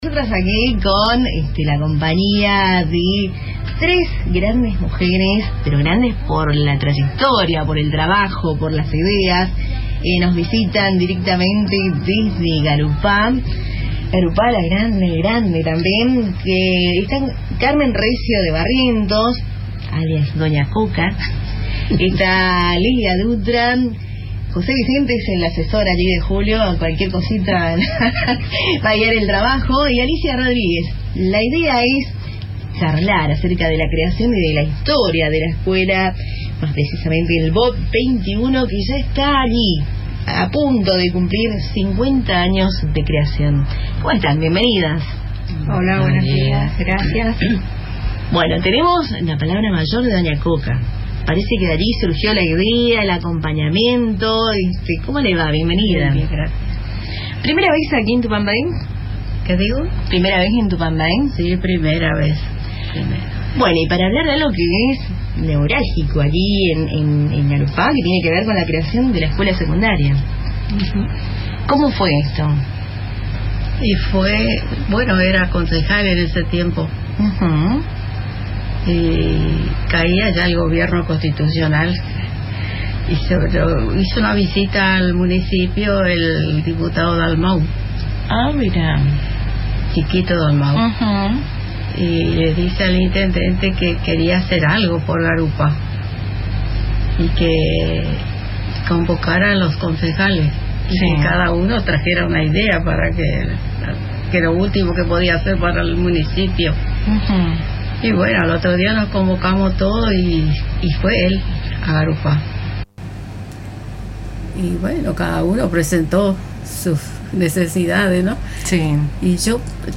En Cultura en Diálogo, se compartió una emotiva entrevista con referentes históricas de la comunidad educativa de Garupá, quienes reconstruyeron los orígenes y el crecimiento del Bachillerato con Orientación Polivalente N° 21 “Arturo Illia”.